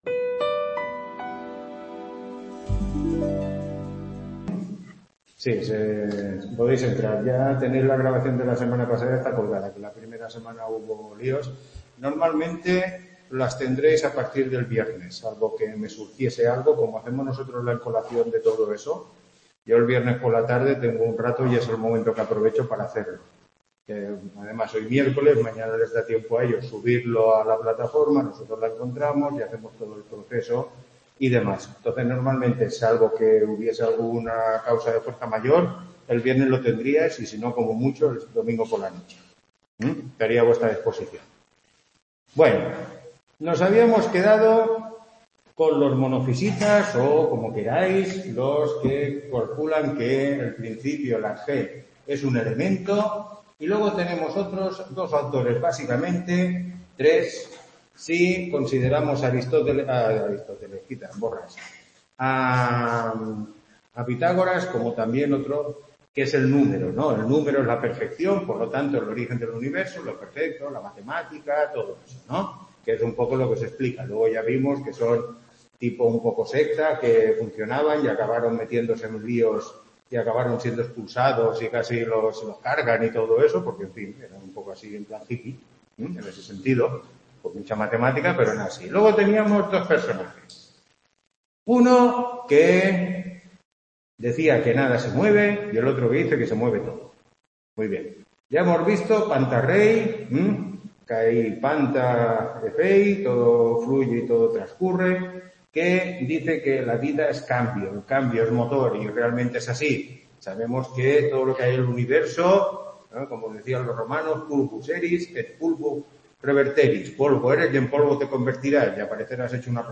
Tutoría 3